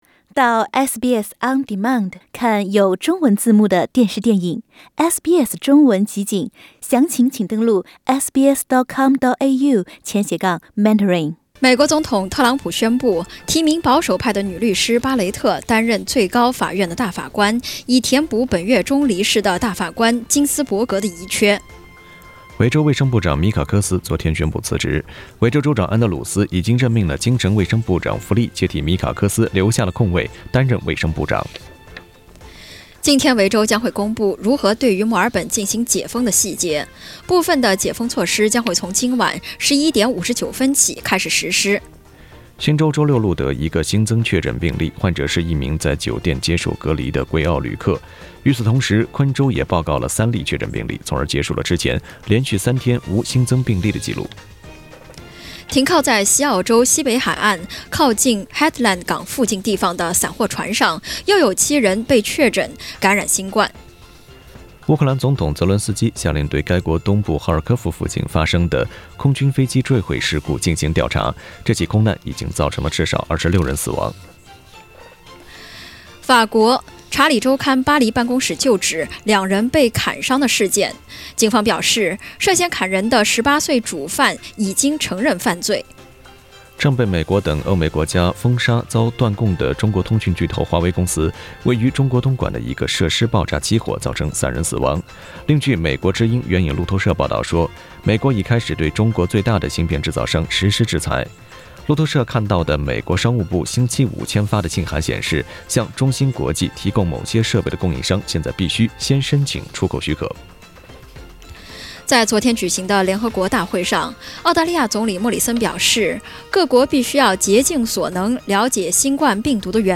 SBS早新闻 (9月27日）